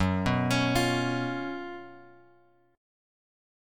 F# Major 7th Suspended 4th